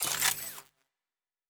Weapon 12 Reload 3 (Laser).wav